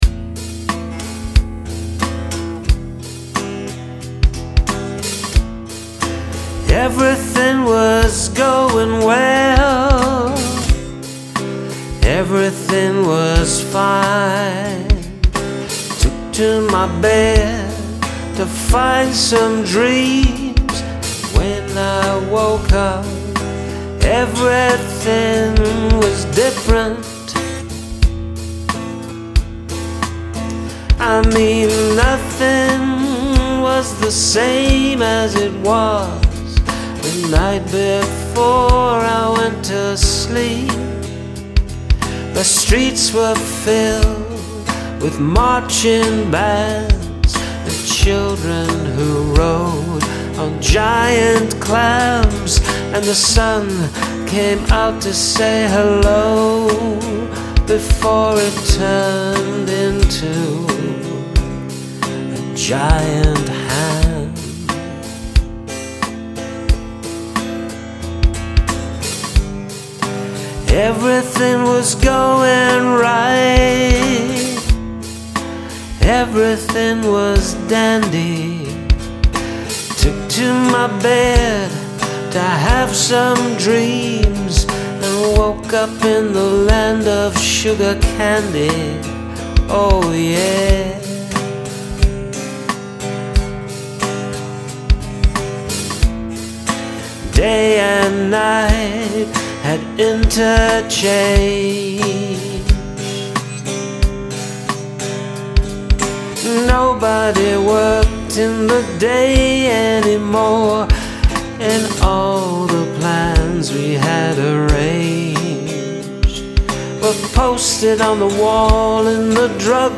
Dream-like and so cool!